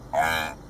Записи сделаны в дикой природе и передают атмосферу саванны.
Звук от гну